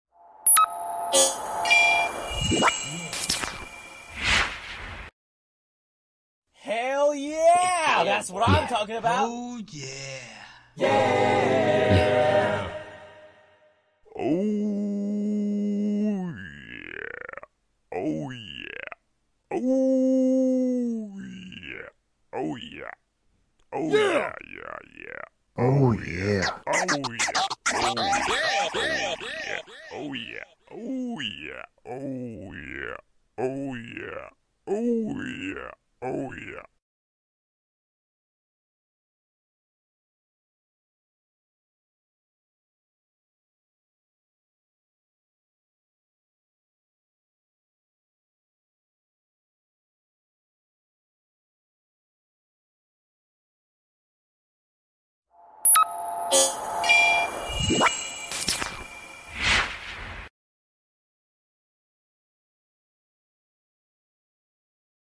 Royalty Free Oh Yeah Sounds sound effects free download